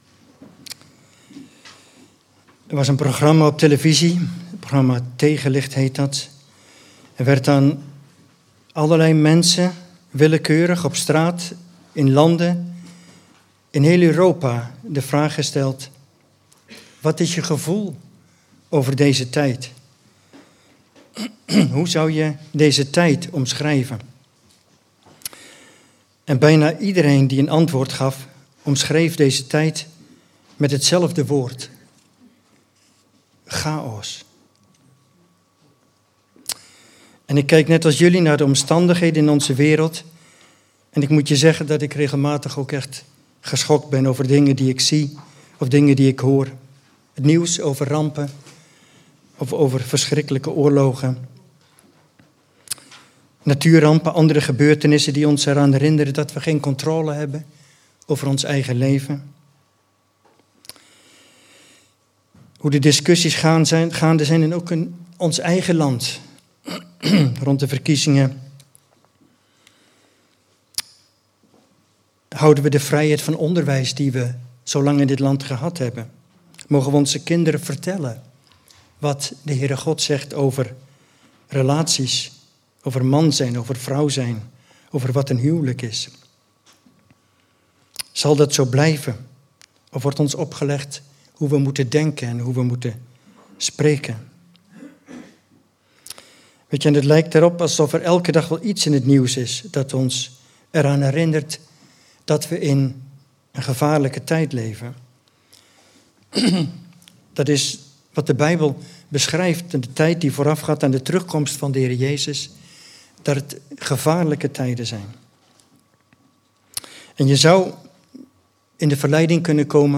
Passage: Romeinen 13:11-14 Dienstsoort: Eredienst « Elia een man Gods in moeilijke tijden.